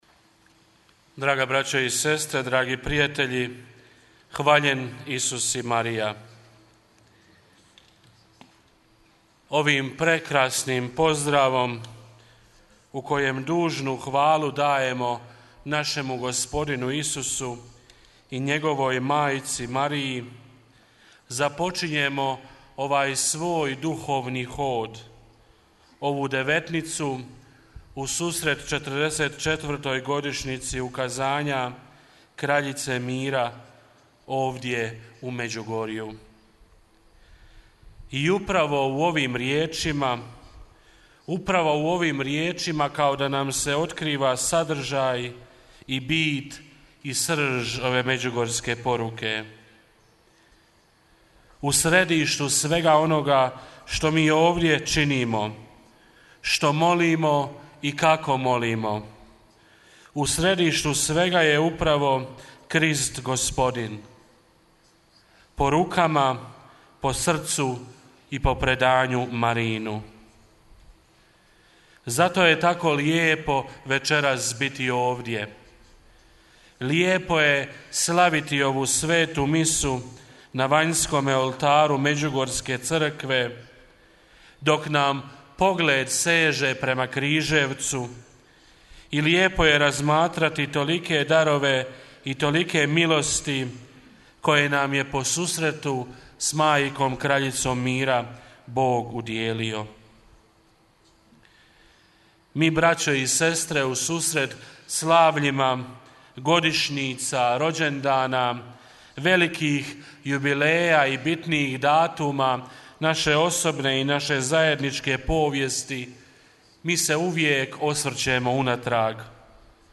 Devetnica Kraljici Mira